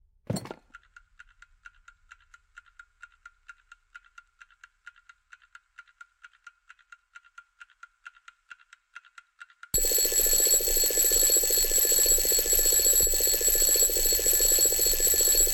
Alarm_Clock_sounds.mp3